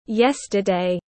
Ngày hôm qua tiếng anh gọi là yesterday, phiên âm tiếng anh đọc là /ˈjes.tə.deɪ/